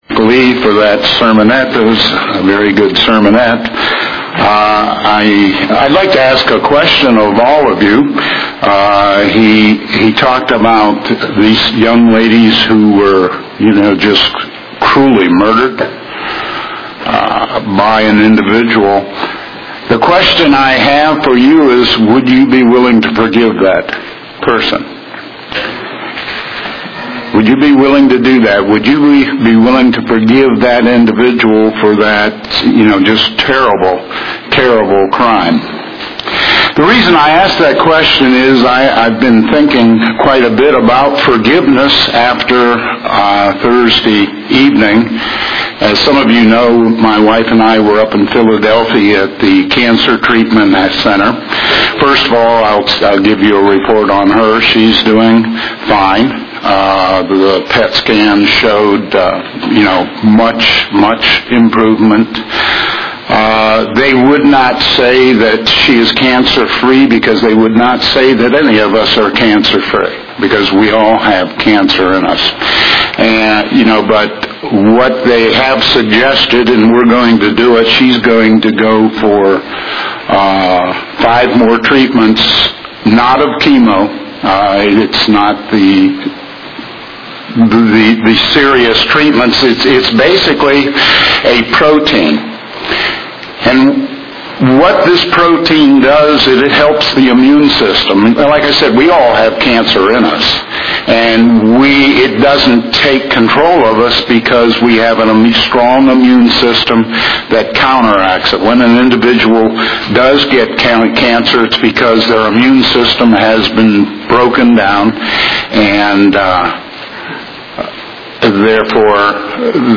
Given in Greensboro, NC
UCG Sermon Studying the bible?